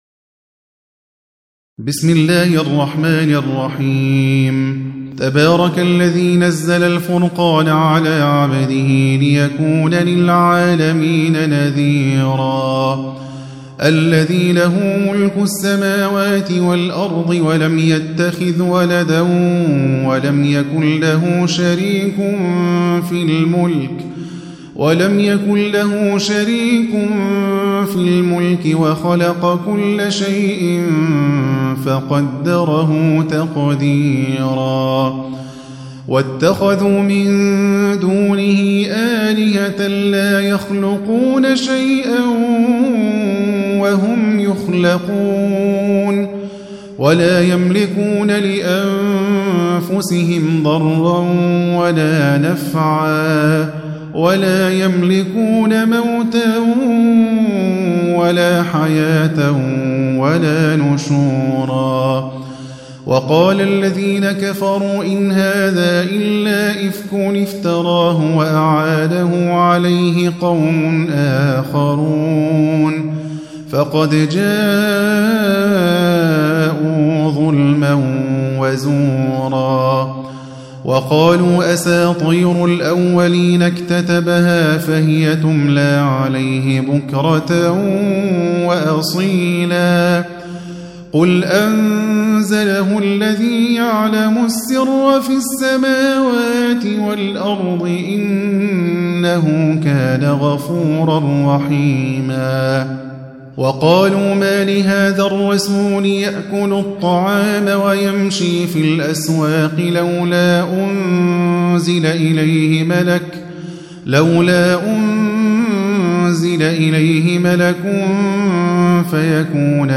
25. Surah Al-Furq�n سورة الفرقان Audio Quran Tarteel Recitation
حفص عن عاصم Hafs for Assem